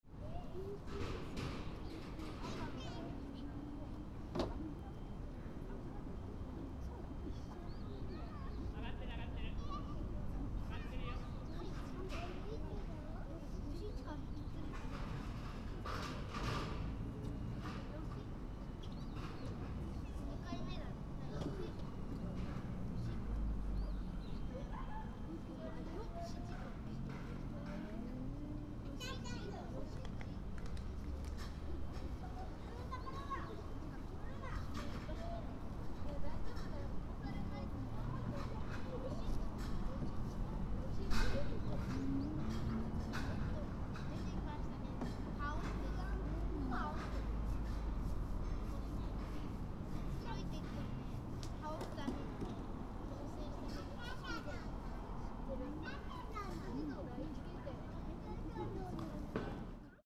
♦ In the warm sunshine in Febrary, some families were playing in the park. ♦ Some birds were twittering around the park.